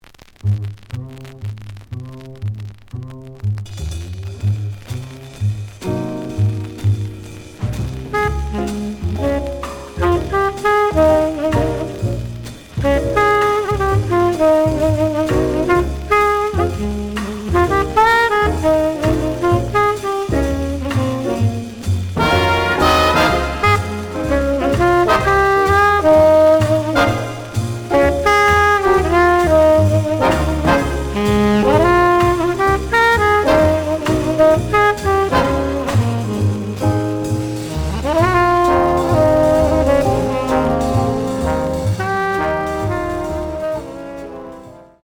The audio sample is recorded from the actual item.
●Genre: Modern Jazz, Cool Jazz